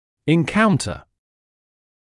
[ɪn’kauntə][ин’каунтэ]встречаться (с чем-л.); наталкиваться (на проблемы, трудности и т.п.)